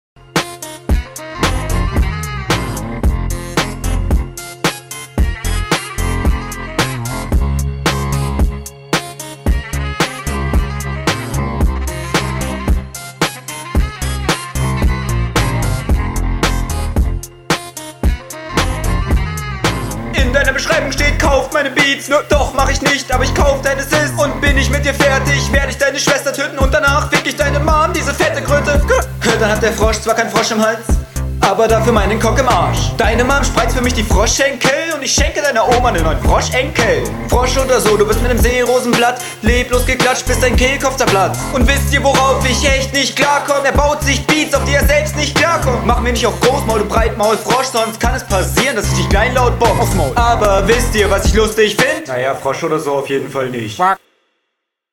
Hinrunde 2
Flow: Recht solider Flow in der Runde, Variationen vorhanden.
Schon mal ein cooler Kopfnickerbeat, bin gespannt...